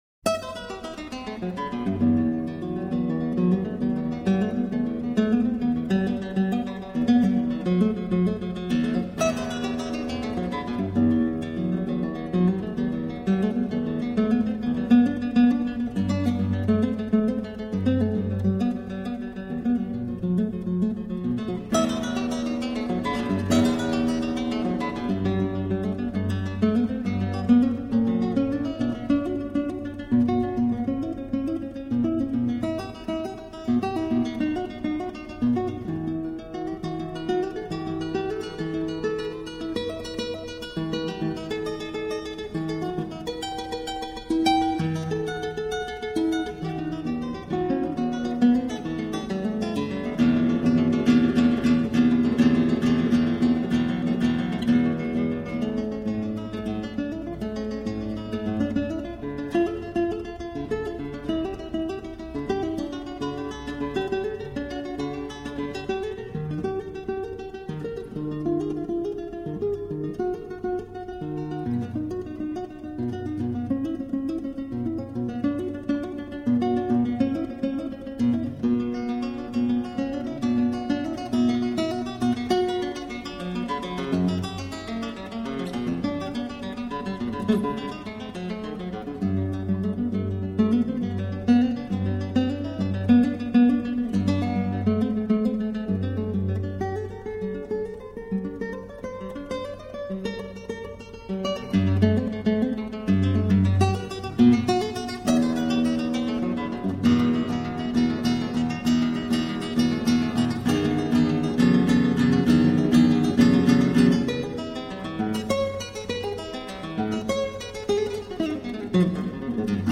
0230-吉他名曲萨帕塔多舞曲.mp3